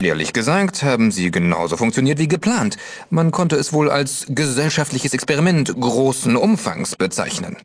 in: Fallout 2: Audiodialoge Datei : PRS36.ogg Quelltext anzeigen TimedText Versionsgeschichte Diskussion Tritt unserem Discord bei und informiere dich auf unserem Twitter-Kanal über die aktuellsten Themen rund um Fallout!